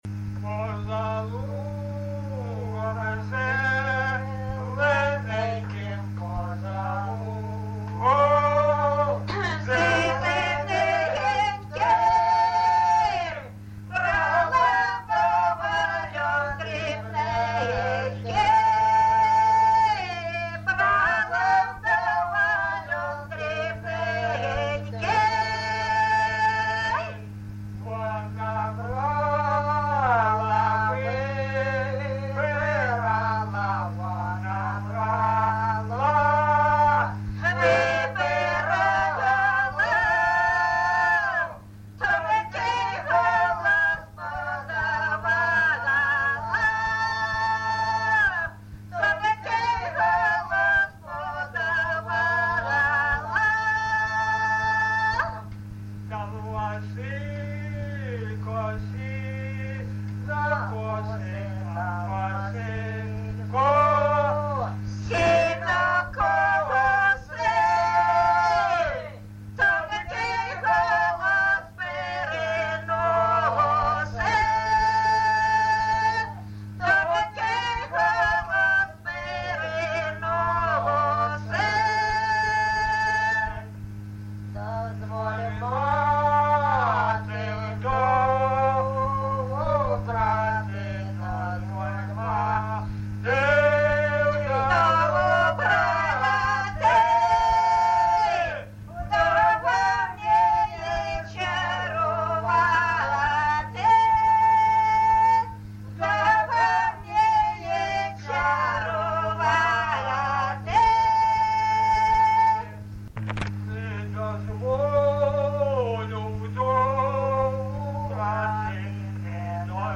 ЖанрПісні з особистого та родинного життя
Місце записус. Бузова Пасківка, Полтавський район, Полтавська обл., Україна, Полтавщина